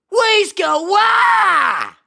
но и чудесные голоса зеленокожих
WAAGH2.mp3